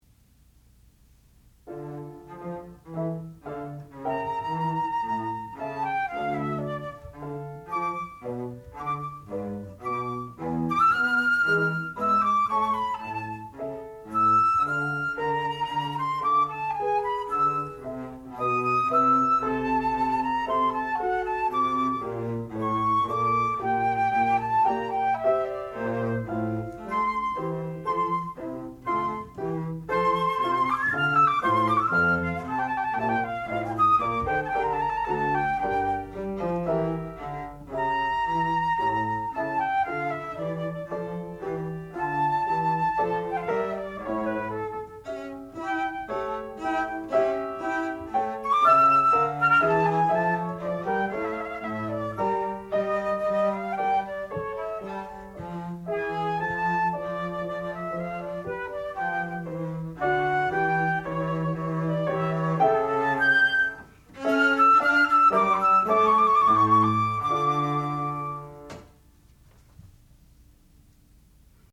sound recording-musical
classical music
violoncello
piano
Qualifying Recital